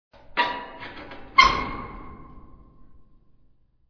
Metal Squeaks
metallic squeaking sounds.
32kbps-MetalSqueeks.mp3